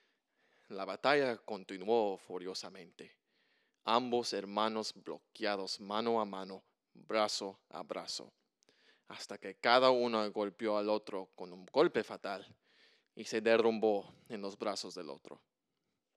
At the beginning of the play Antígona and Ixiim are listening to the radio announce and introduce us to the political and environmental tensions of the play. This is meant to be coming from an old fashioned radio. An SM58, Scarlett, and Logic Pro were used to create the speech.